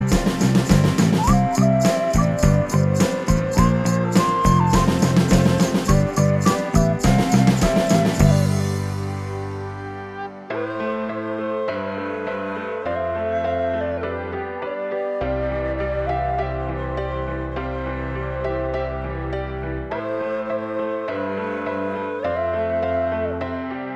Minus Acoustic Guitar Rock 3:20 Buy £1.50